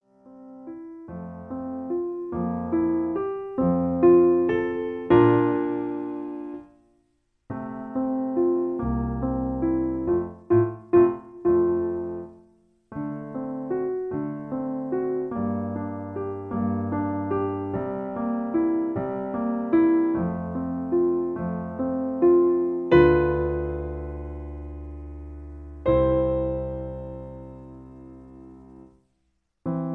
In F. Piano Accompaniment